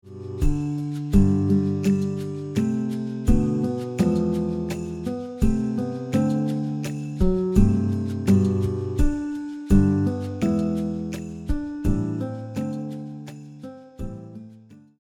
• Guitar arrangement